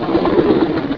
doorSlide2.wav